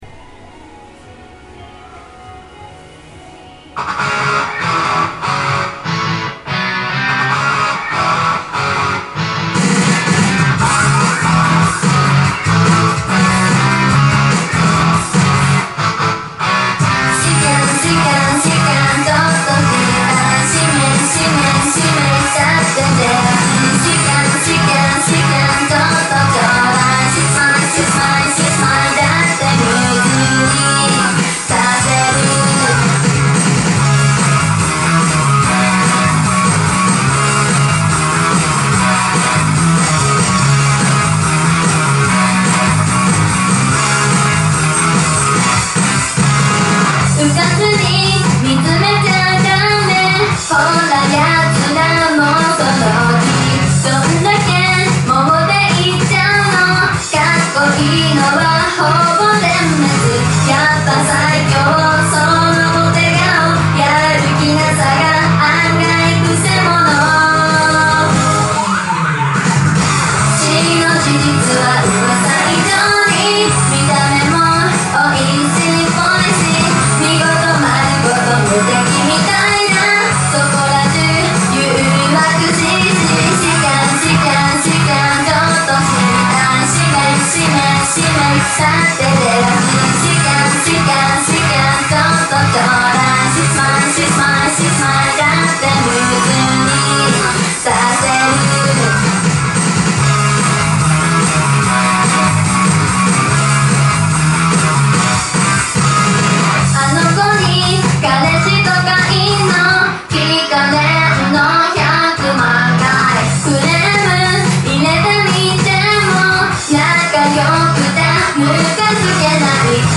オフマイク／音楽収録用マイクロフォン使用